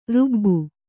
♪ rubbu